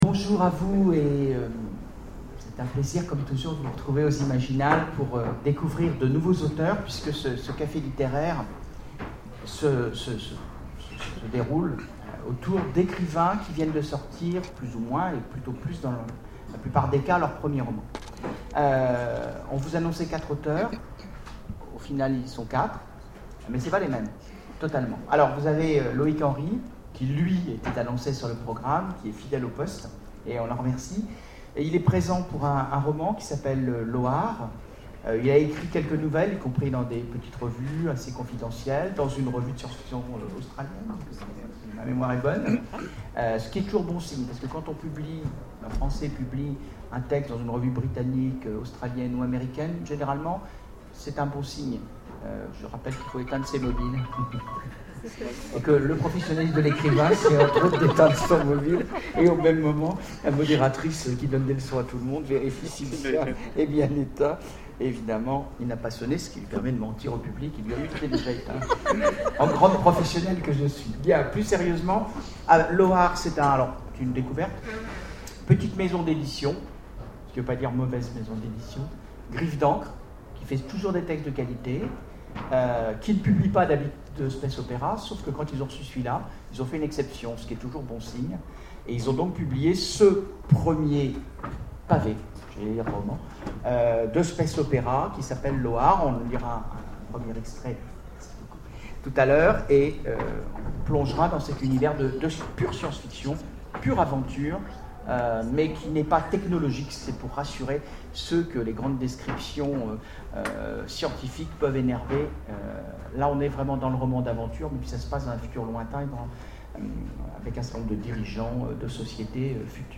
Imaginales 2012 : Conférence Premiers romans... vraies découvertes !
Imaginales2012Premierroman.mp3